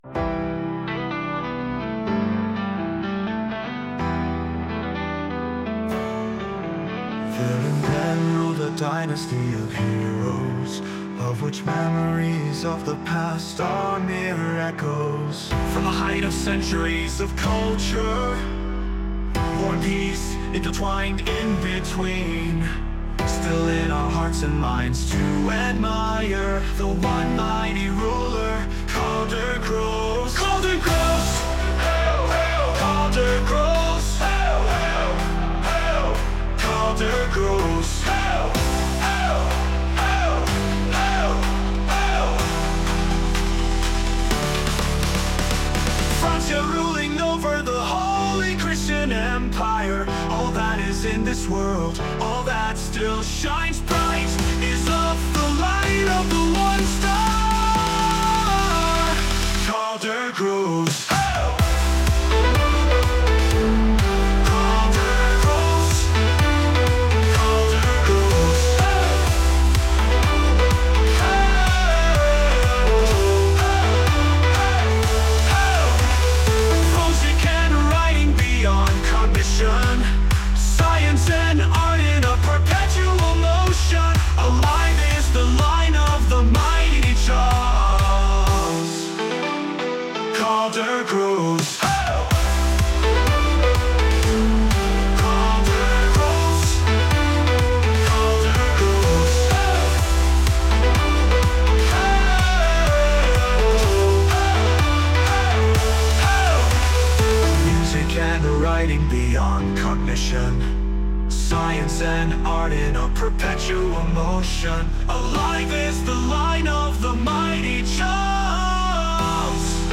random AI song, Karl Der Gros (Hail!).